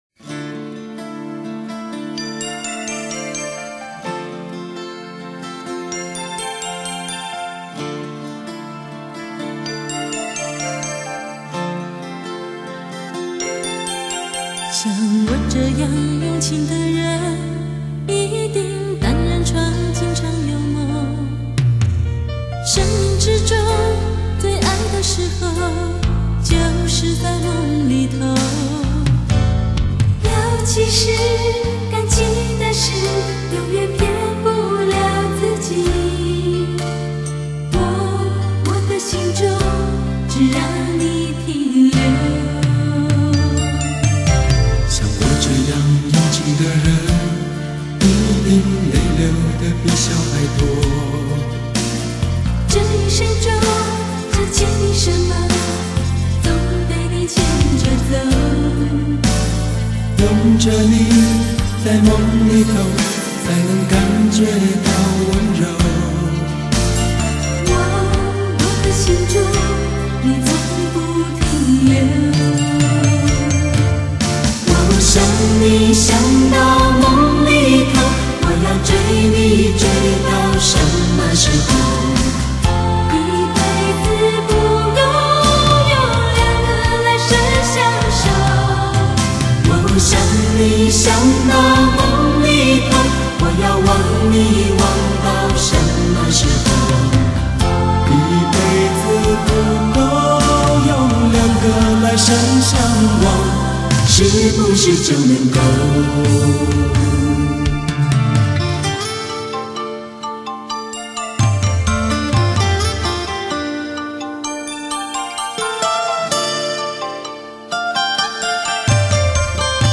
德国后期母带制作
JVC独有KZ-20Bit模拟/数码转换